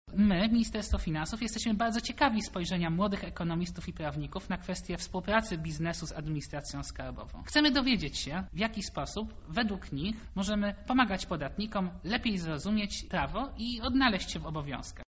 – dodaje Sarnowski